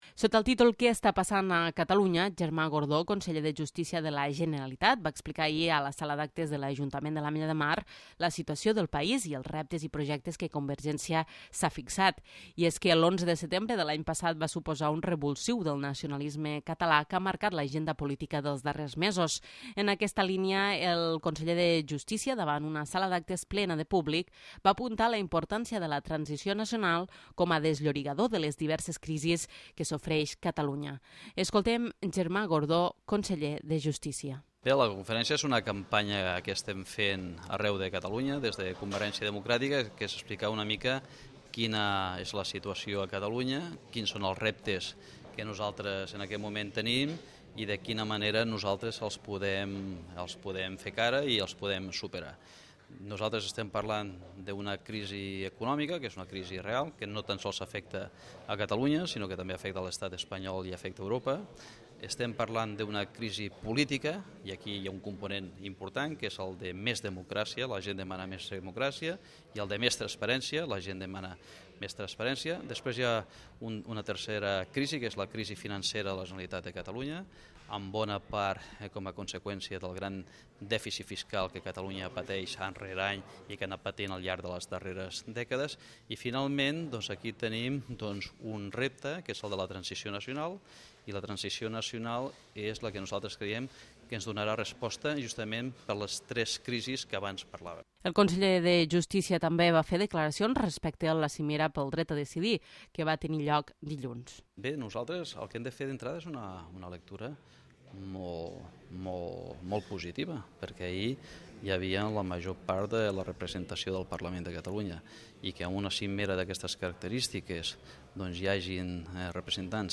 Germà Gordó, conseller de Justícia de la Generalitat va realitzar una conferència el 7 de maig a la sala d'actes de l'Ajuntament de l'Ametlla de Mar.